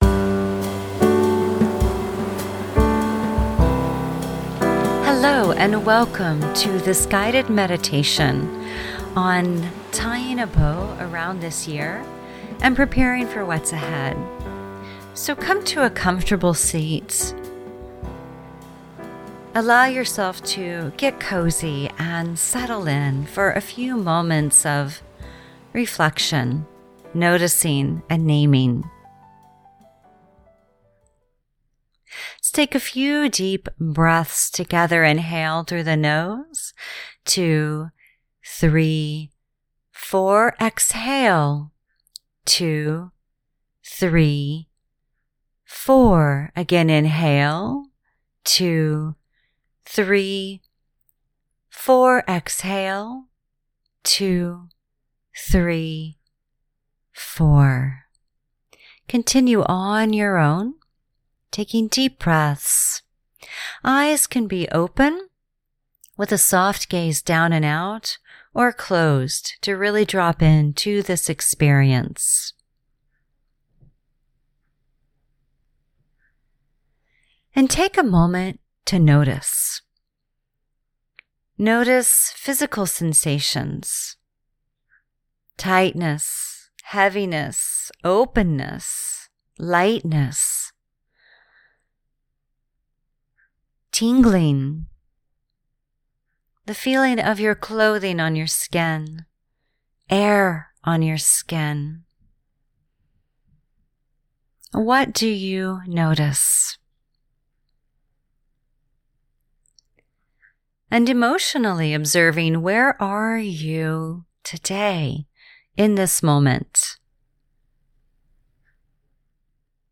pink-friday-meditation.mp3